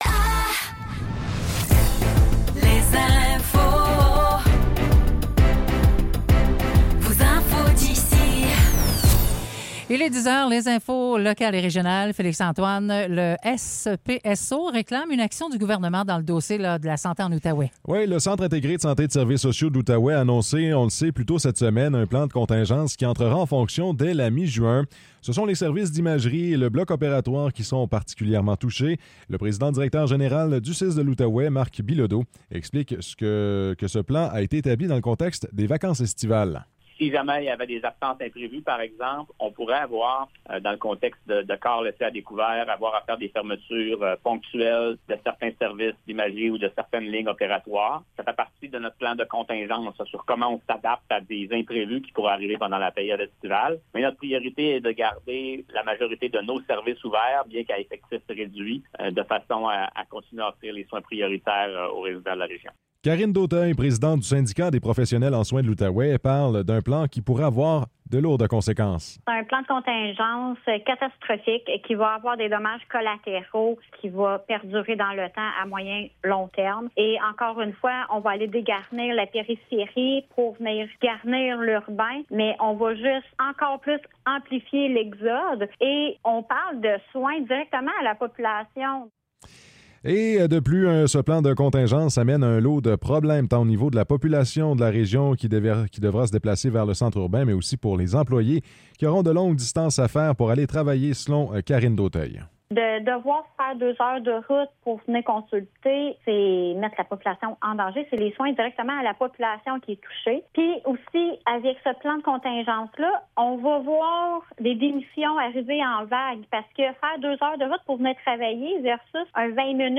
Nouvelles locales - 24 mai 2024 - 10 h